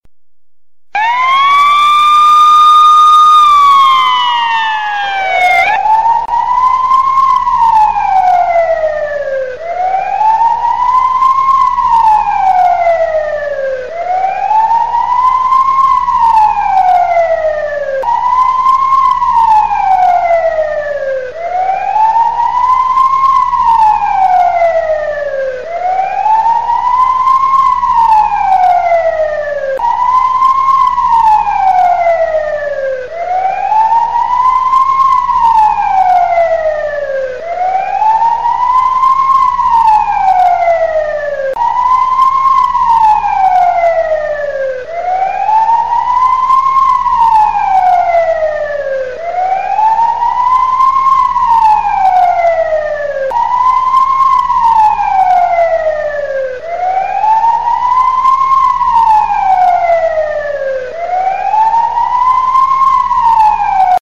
fireAlarm.mp3